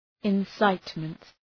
{ın’saıtmənt}
incitement.mp3